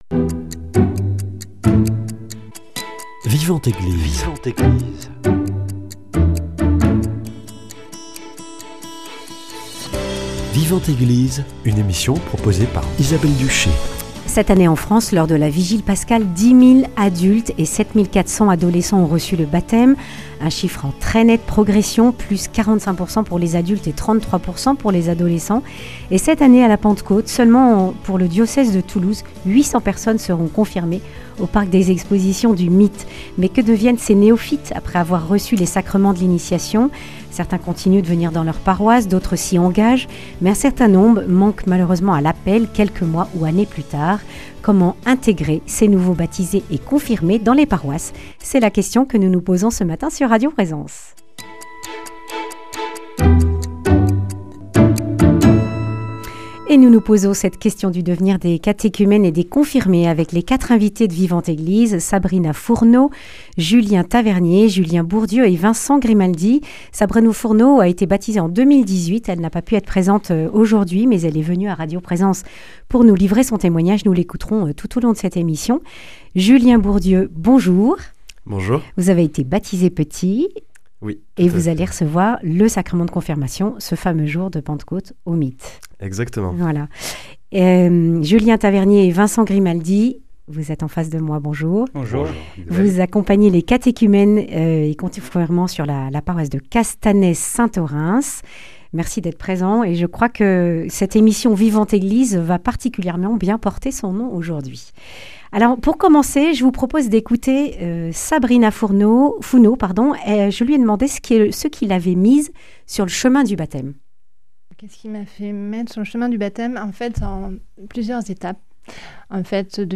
Ils témoignent de leur engagement paroissial.